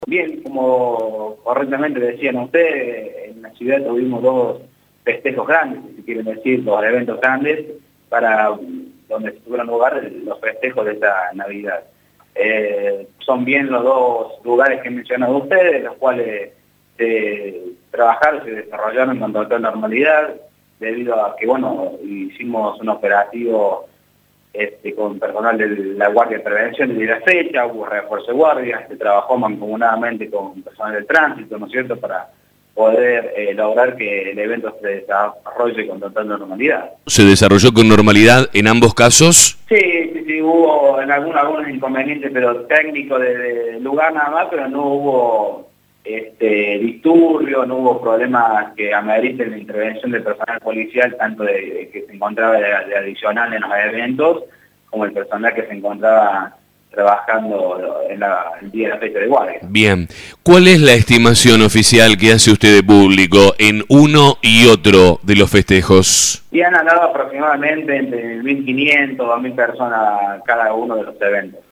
Escuchá el informe policial.